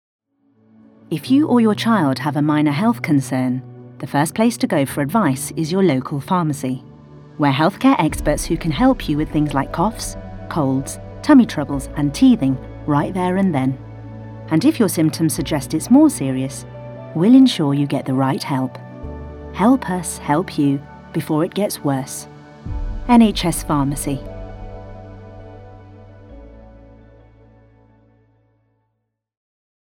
40/50s, Midlands/Indian/RP, Warm/Versatile/Experienced